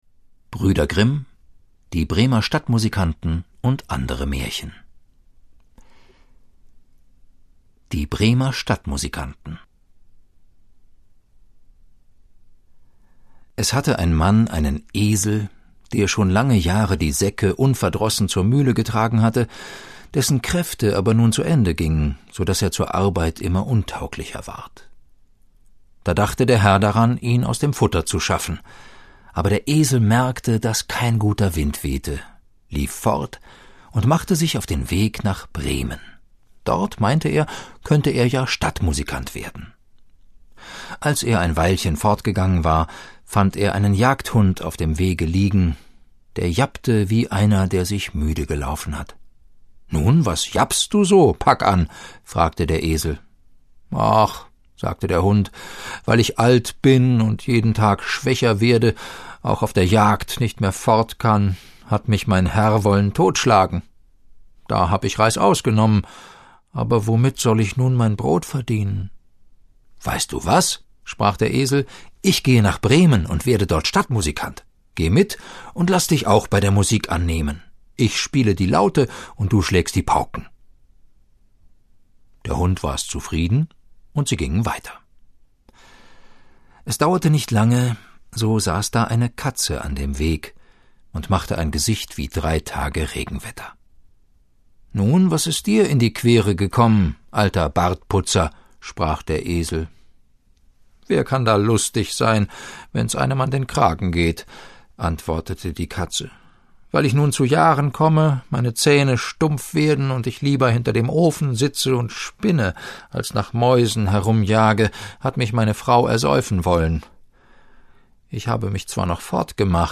Die schönsten Märchen der Brüder Grimm als Hörbuch: Die Bremer Stadtmusikanten - Rapunzel - Frau Holle - Der Teufel mit den drei goldenen Haaren - König Drosselbart - Der Froschkönig - Der Hase und der Igel - Schneewittchen - Rumpelstilzchen - Dornröschen - Das tapfere Schneiderlein - Aschenputtel - Das weiße Kalb - Fingerhütchen - Der kleine Sackpfeifer - Die erzürnten Elfen - Die Flasche - Rotkäppchen - Die Sterntaler - Hänsel und Gretel - Die goldene Gans - Tischleindeckdich, Goldesel und KnüppelausdemSack - Das Eselein - Der Wolf und die sieben jungen Geißlein - Brüderchen und Schwesterchen - Die Prinzessin auf der Erbse - Schneeweißchen und Rosenrot.